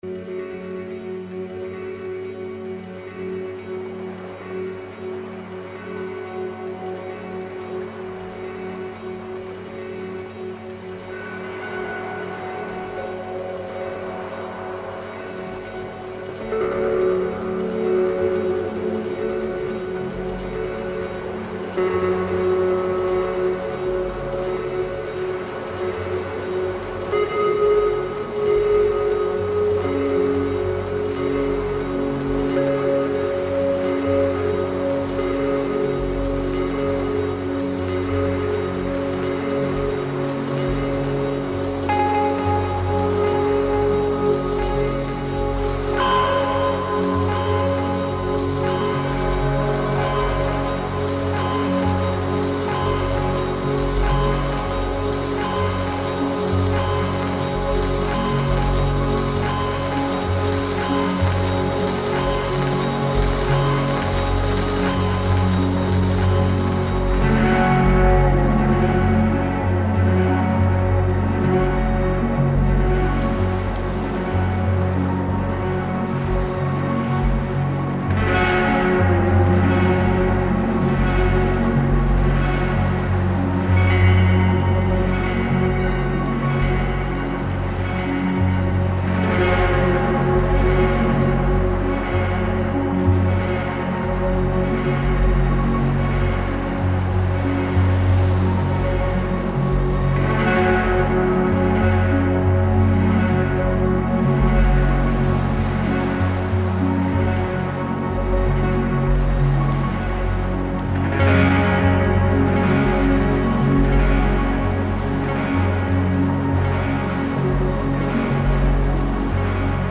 Waves in Sooke, Canada reimagined